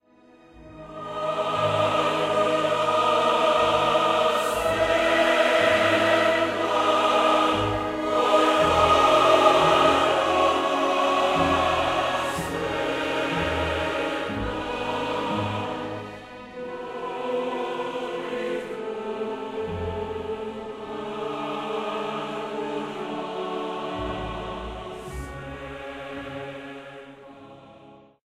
Film score
Key G-sharp minor
Time signature 4/4